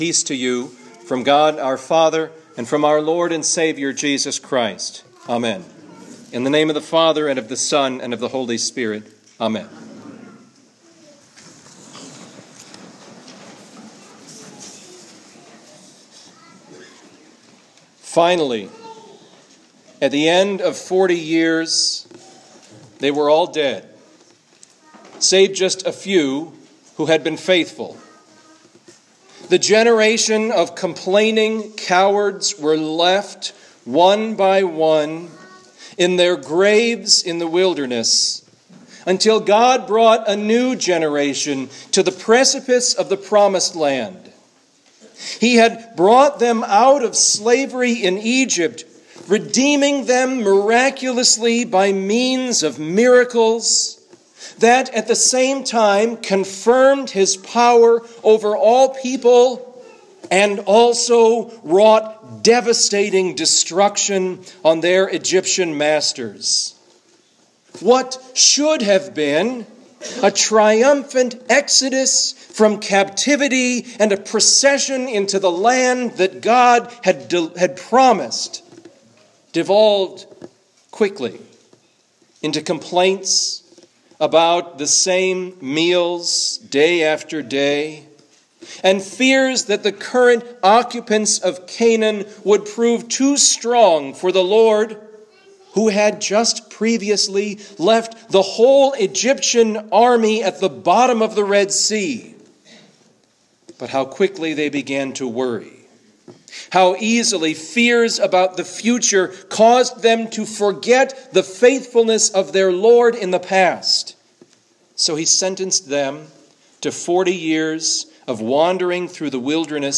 Home › Sermons › Trinity 21 – 40th Anniversary